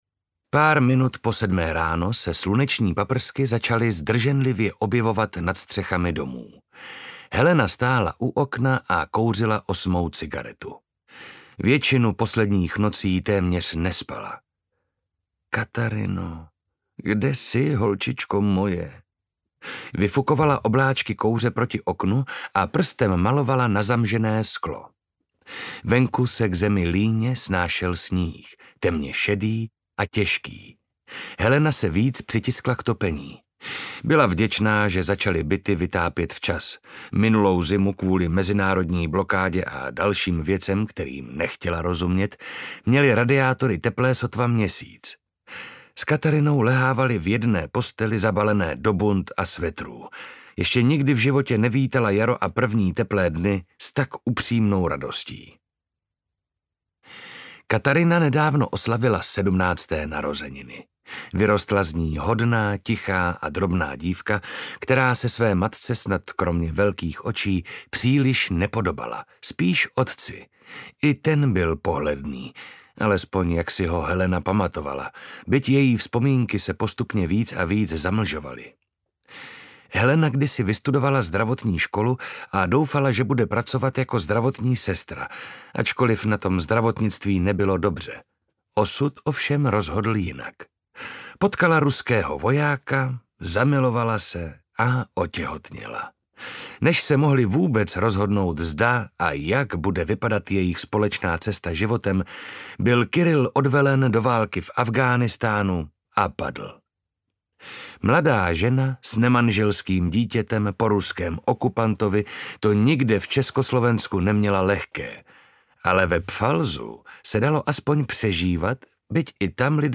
Audio knihaVšechny tváře smrti
Ukázka z knihy
• InterpretMarek Holý